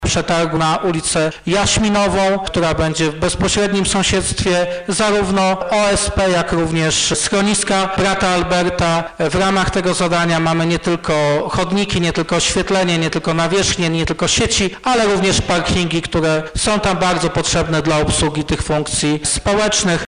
O zakresie przebudowy, jakiej doczeka się Jaśminowa mówił prezydent Stalowej Woli Lucjusz Nadbereżny: